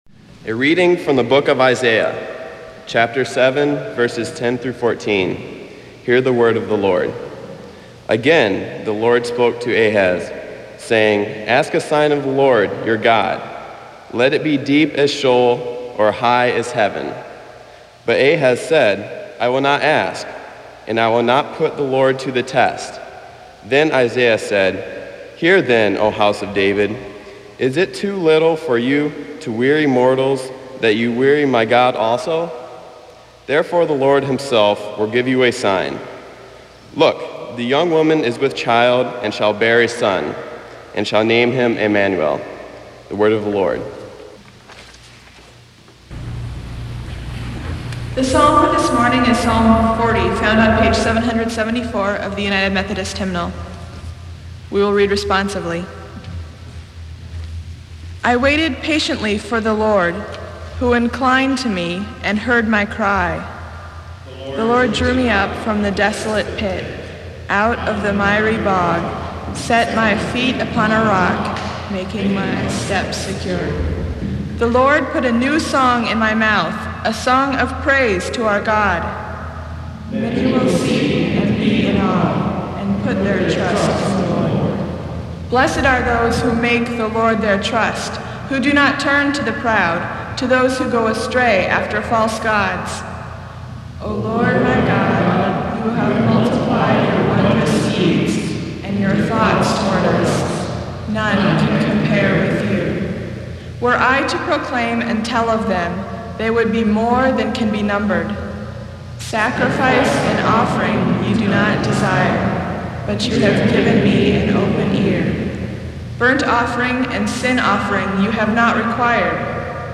A sermon focusing on Mary, the mother of Jesus. Scriptures 7:10-14, Psalm 40, Luke 1:28-38.